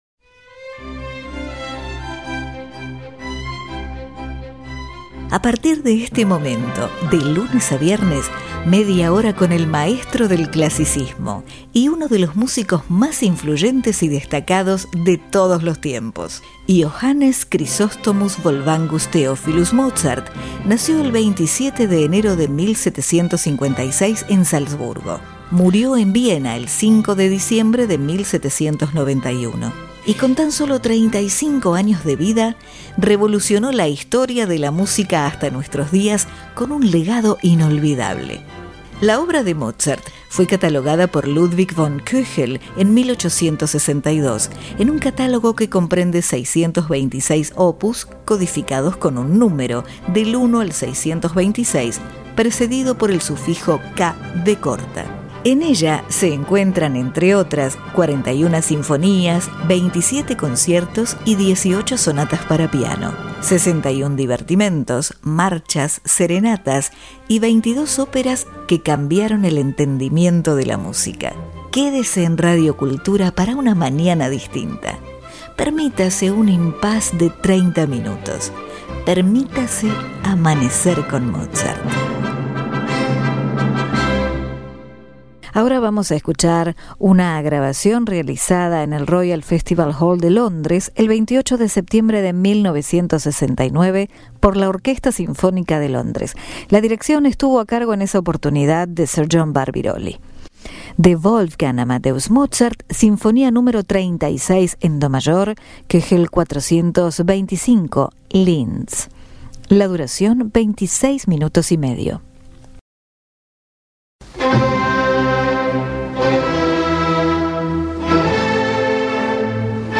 Sinfonia Nº 36 En Do Mayor
Orquesta Sinfónica De Londres Sir John Barbirolli